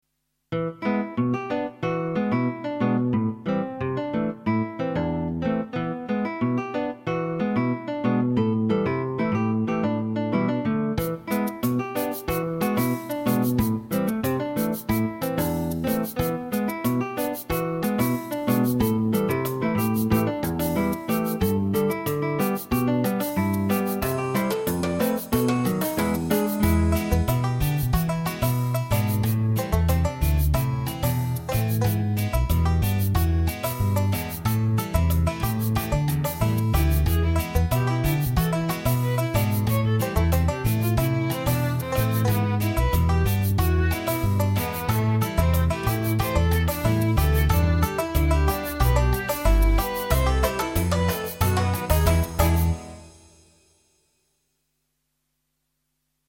Hierbij een aantal fragmenten op basis van inheemse en folkloristische instrumenten.
Ultimate Twins Mix van folk, jazz en country 0:56 884kb 2002 Een uptime 'rag-jazz' gitaarstukje, neigt naar het einde naar Amerikaanse folk.